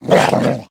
Minecraft Version Minecraft Version latest Latest Release | Latest Snapshot latest / assets / minecraft / sounds / mob / wolf / sad / growl1.ogg Compare With Compare With Latest Release | Latest Snapshot
growl1.ogg